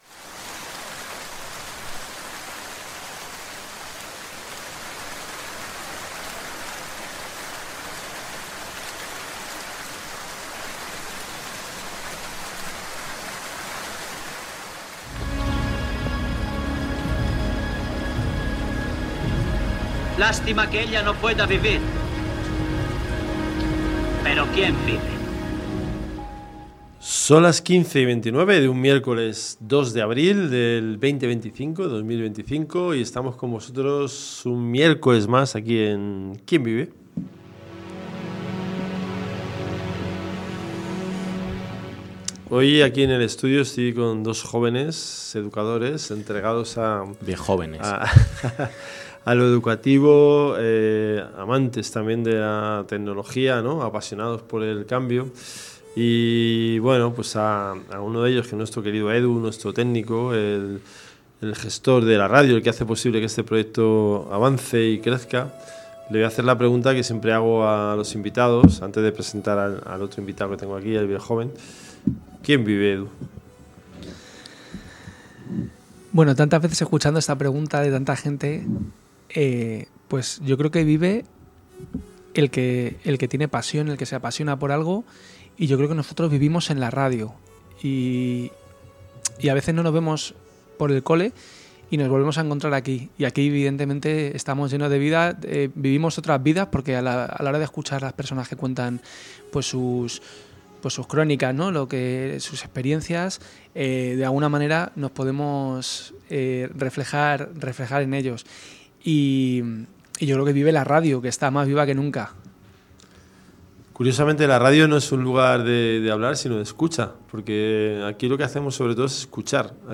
se sientan a debatir sobre un tema que nos toca a todos